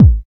80 KICK 3.wav